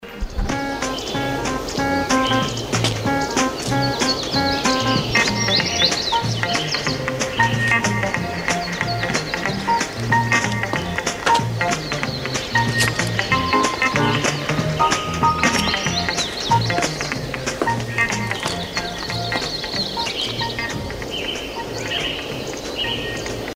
оркестровая пьеса